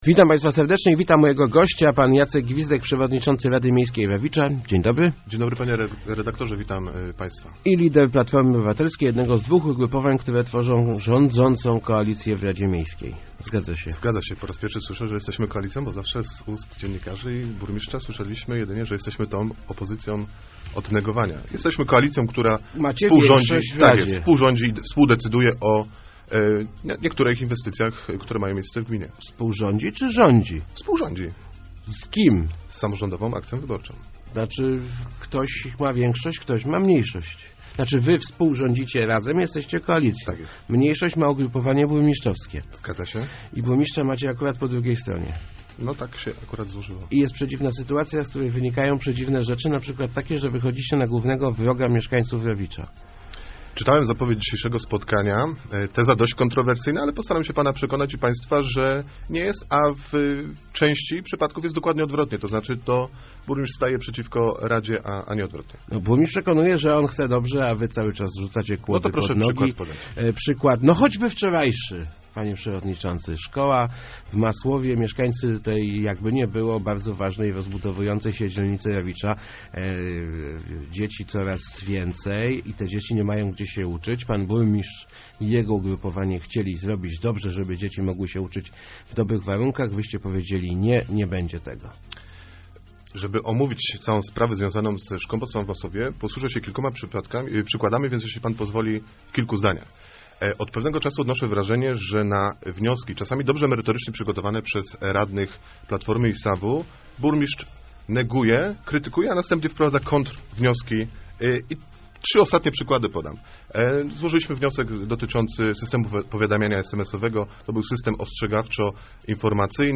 Zostaliśmy zupełnie zaskoczeni wnioskiem burmistrza w sprawie rozbudowy szkoły w Masłowie - mówił w Rozmowach Elki Jacek Gwizdek, przewodniczący Rady Miejskiej Rawicza.